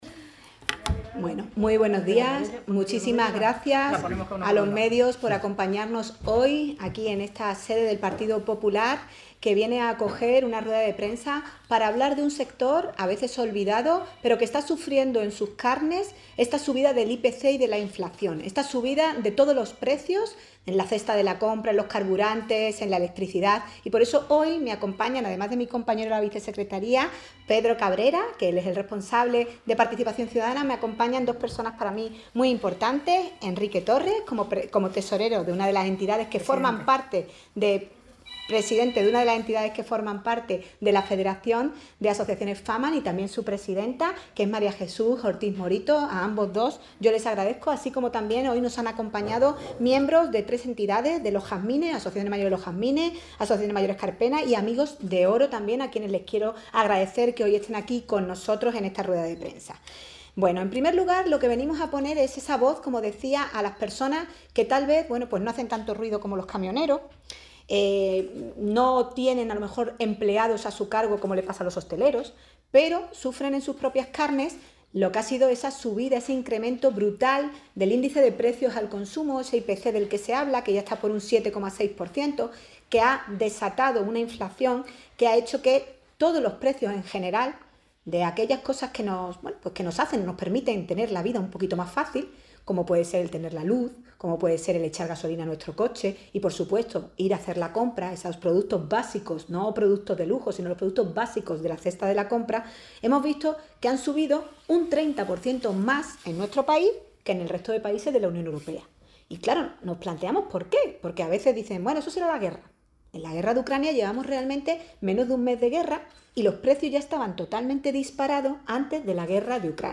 Así lo ha expuesto en rueda de prensa